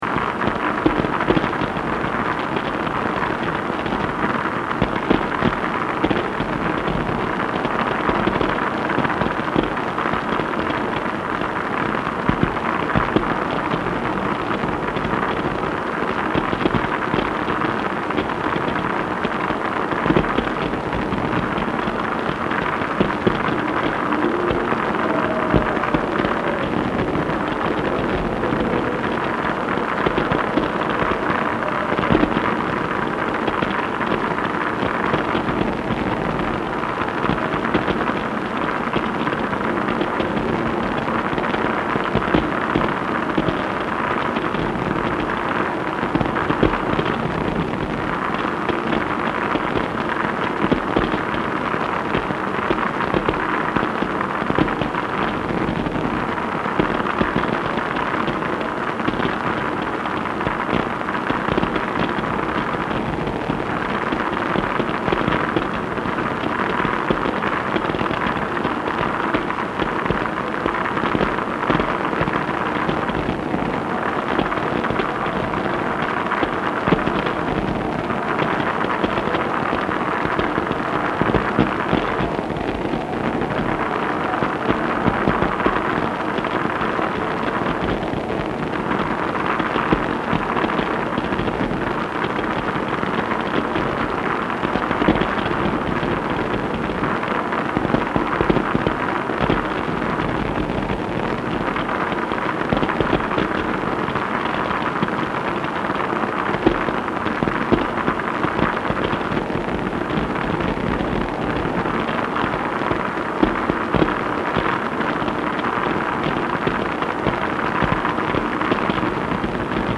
(Boston, MA)
HNW project